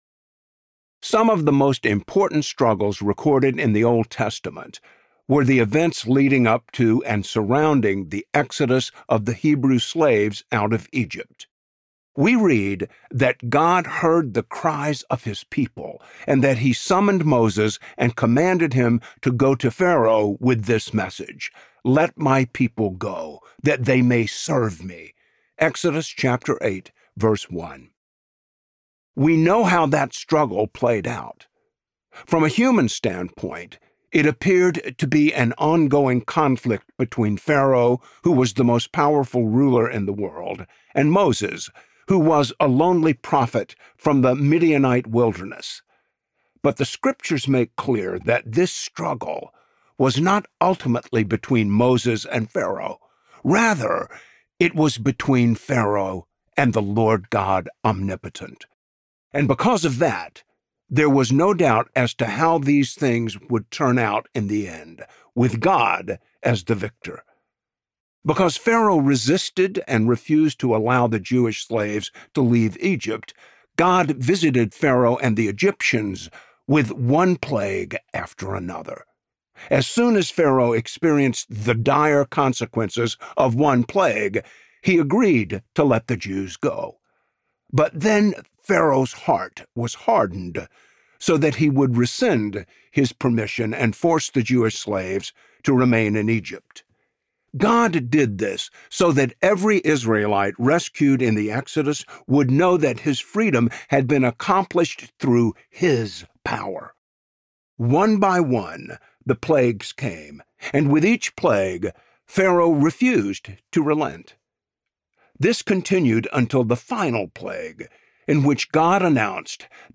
Holy Week: R.C. Sproul - Audiobook Download, Book | Ligonier Ministries Store